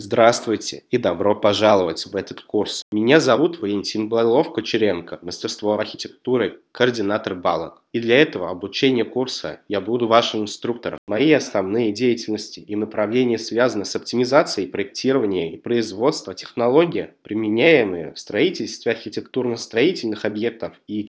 Режим: Видео + озвучка (Русский)